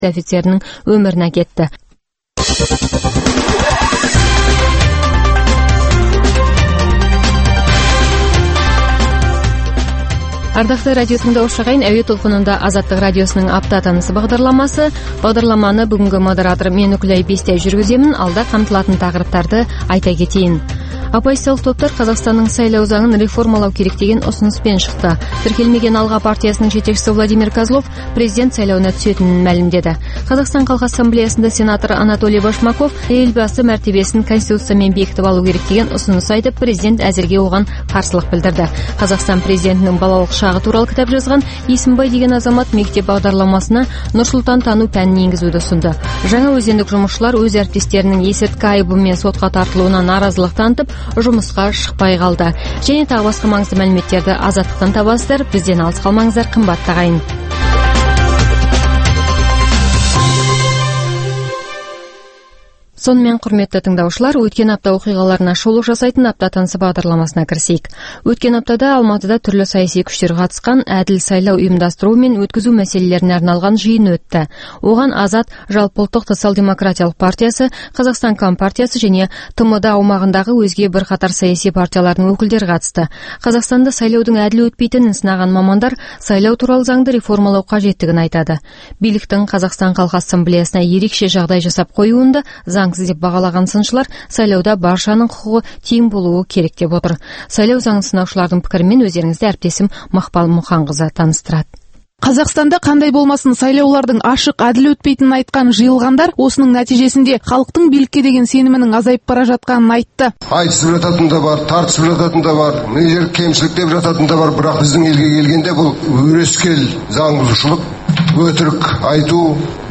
Апта тынысы – Апта бойына орын алған маңызды оқиға, жаңалықтарға құрылған апталық шолу хабары.